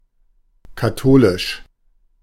Ääntäminen
Ääntäminen US US : IPA : /ˈɹəʊm.ən/